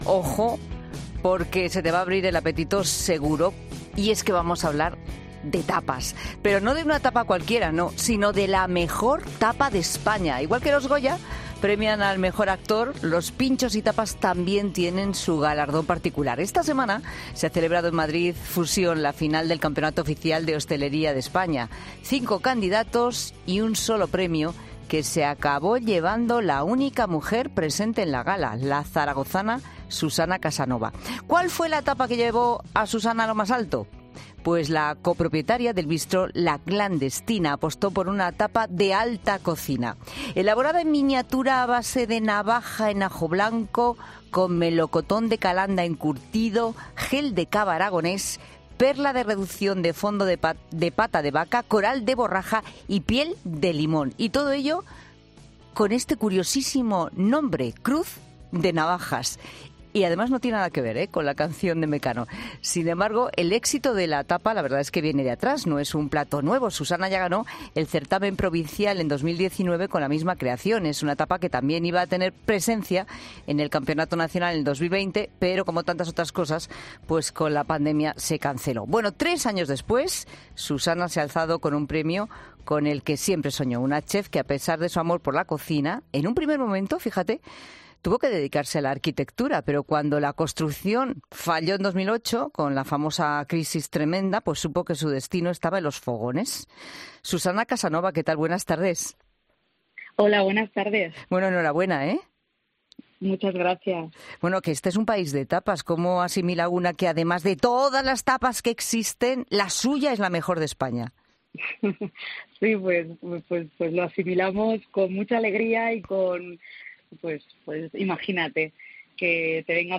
Así, que ha calificado este viernes en La Tarde de COPE como algo que reciben “con mucha alegría”.